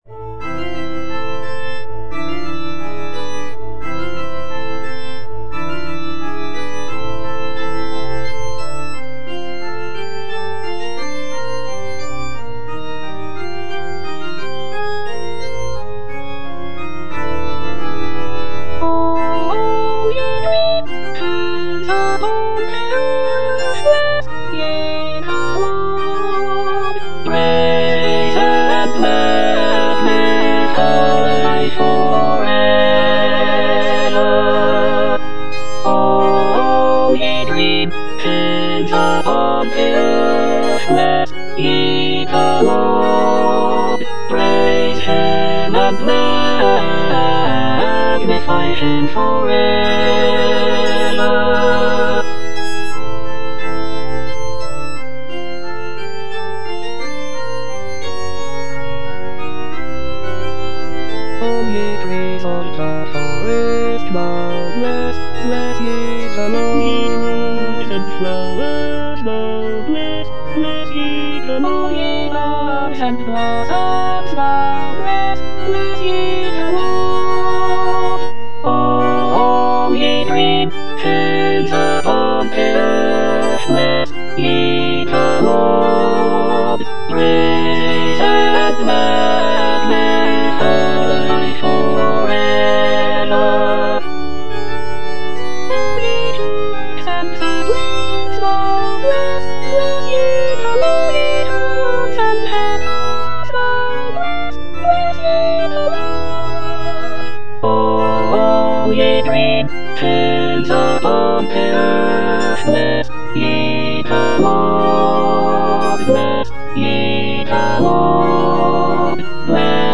choral work